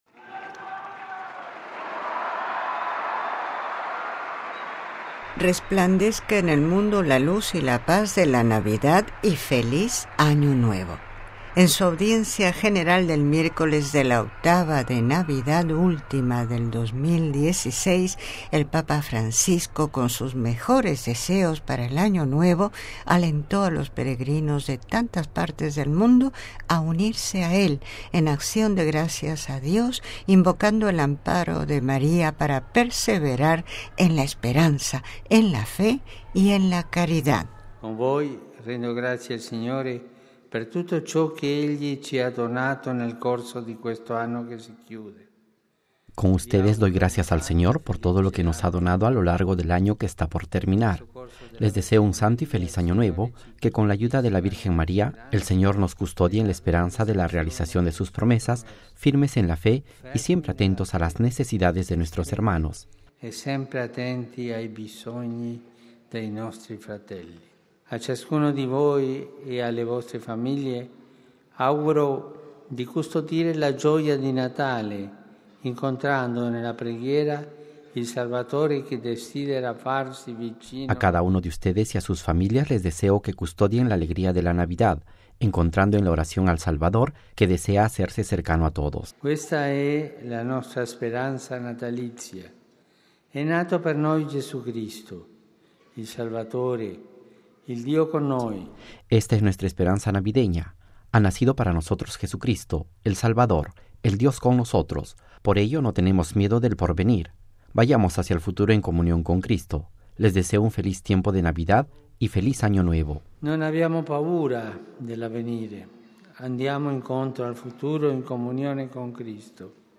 (RV).- En su audiencia general del miércoles de la octava de Navidad, última del 2016, el Papa Francisco, con sus mejores deseos para el Año Nuevo, alentó a los peregrinos de tantas partes del mundo a unirse a él, en acción de gracias a Dios, invocando el amparo de María para perseverar en la esperanza, en la fe y en la caridad: